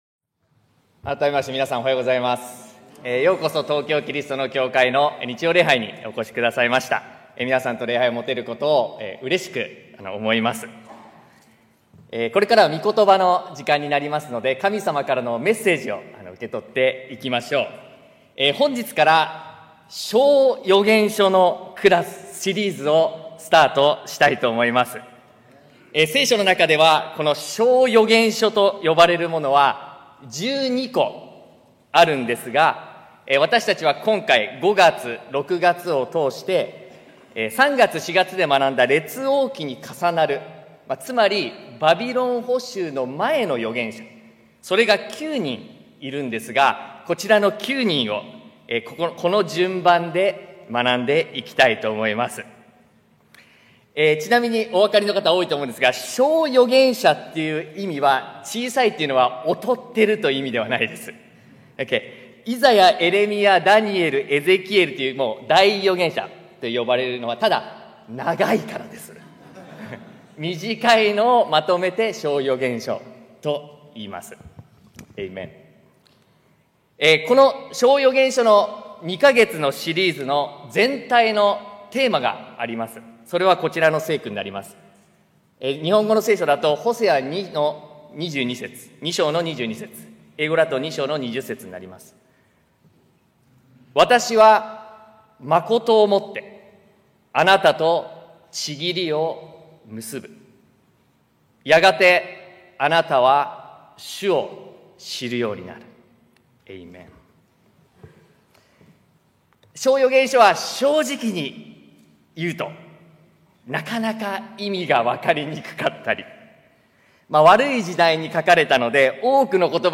東京キリストの教会 日曜礼拝説教